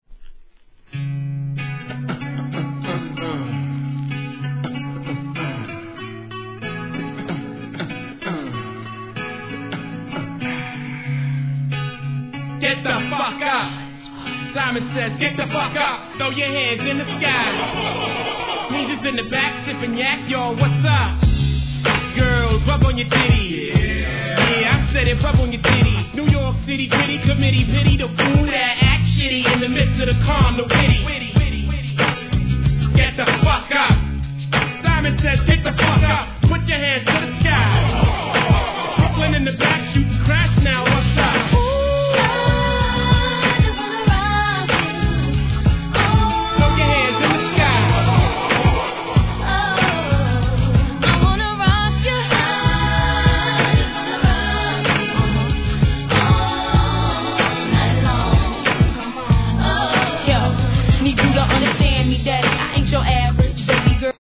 1. HIP HOP/R&B
人気のブレンド、REMIXシリーズ!!!